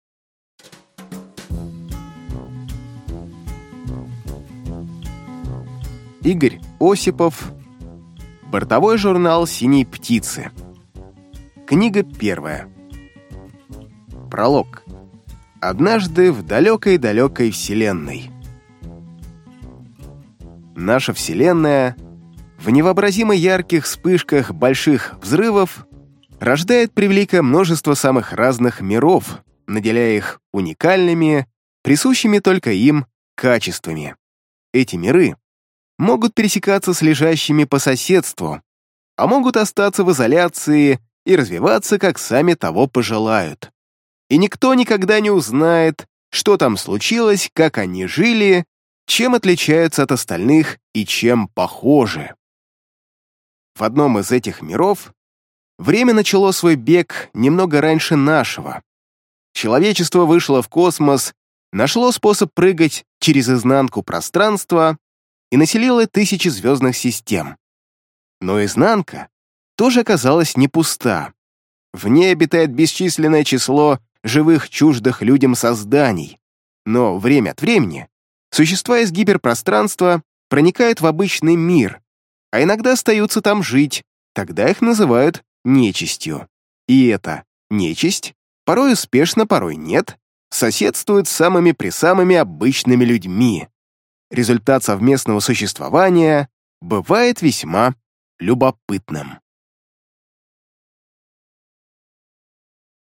Аудиокнига Бортовой журнал «Синей птицы». Книга 1 | Библиотека аудиокниг